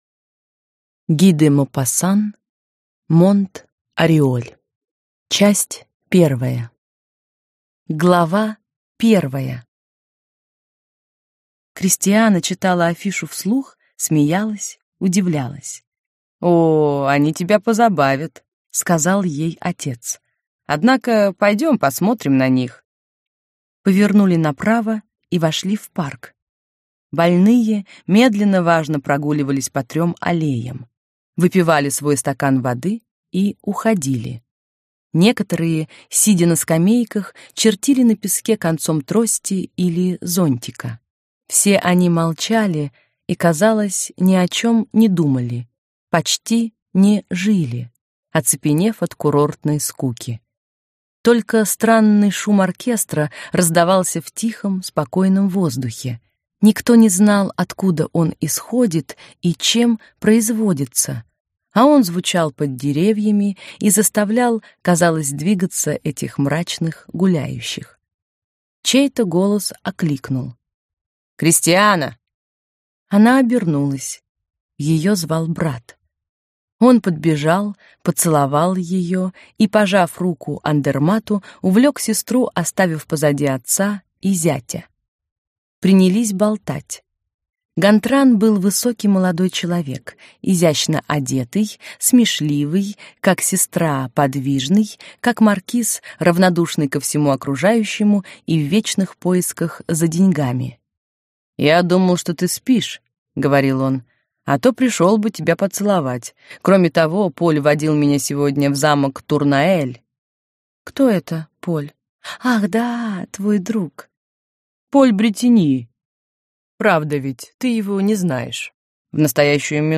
Аудиокнига Монт-Ориоль | Библиотека аудиокниг